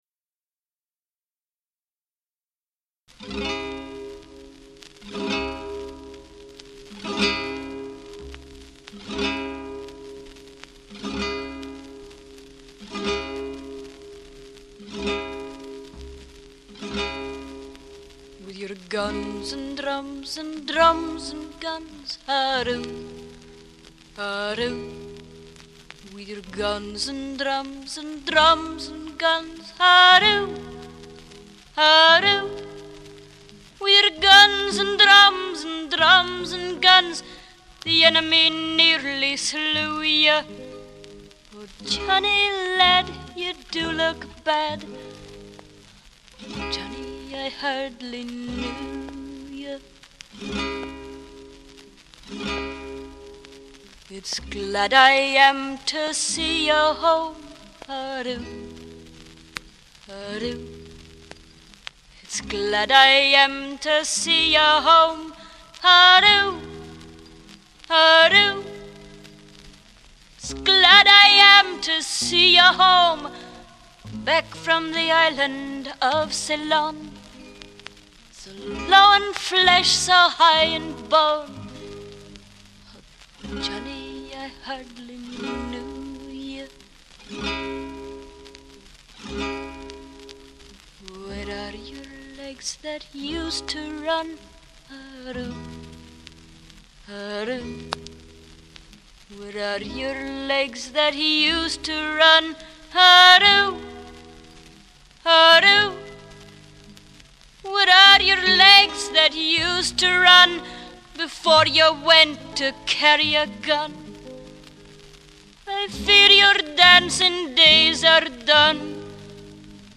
as well as her naturally fresh vocal quality.